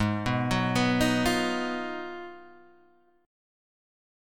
G# Minor 13th